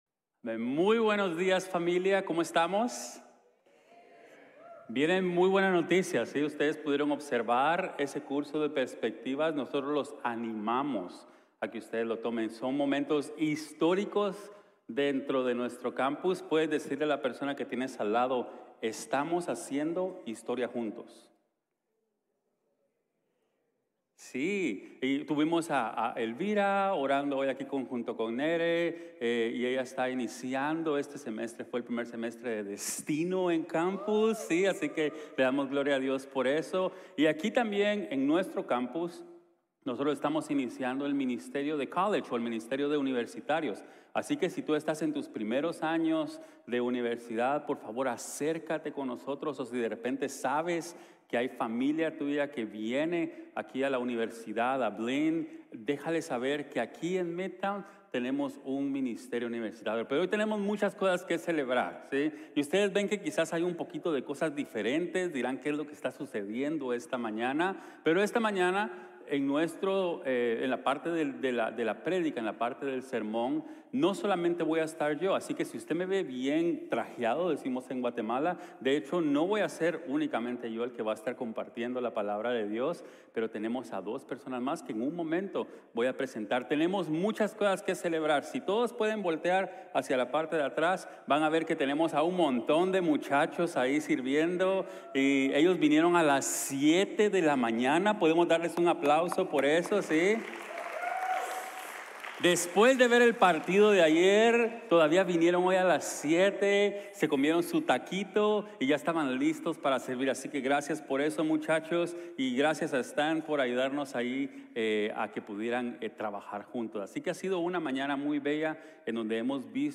Personas comunes, llamado Extraordinario | Sermon | Grace Bible Church